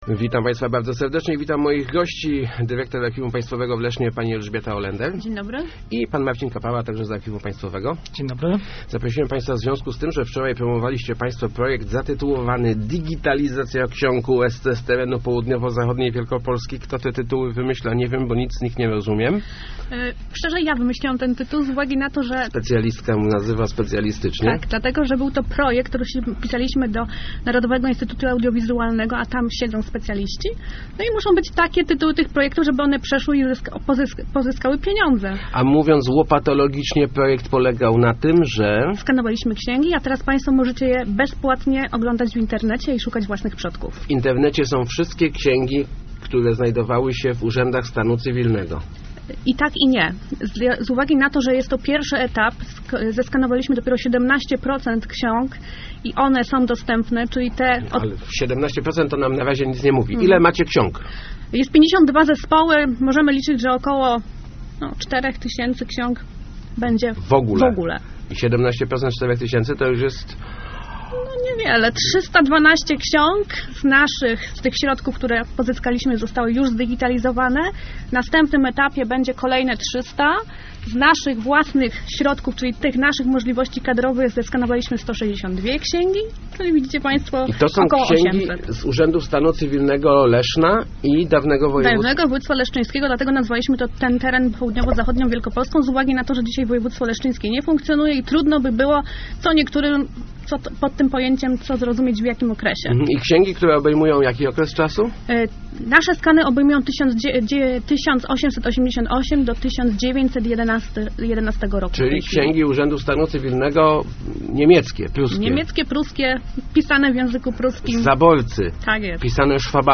Start arrow Rozmowy Elki arrow Stwórz swoją domową historię